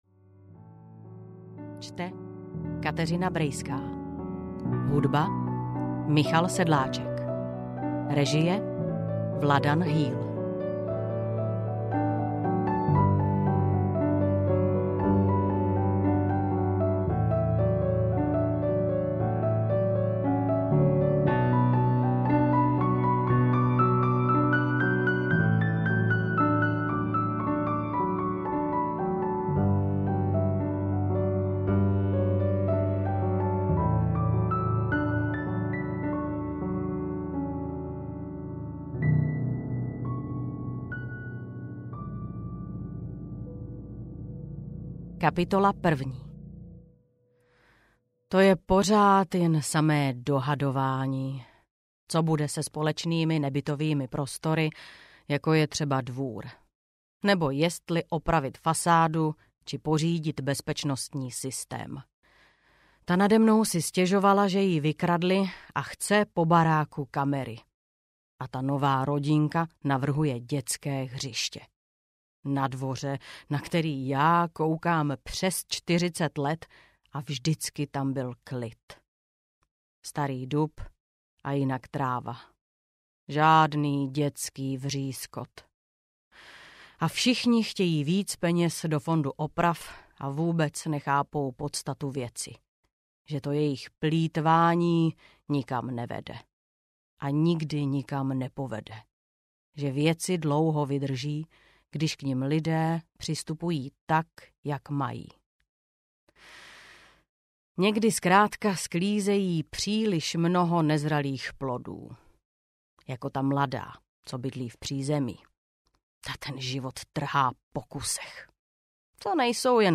Barák audiokniha
Ukázka z knihy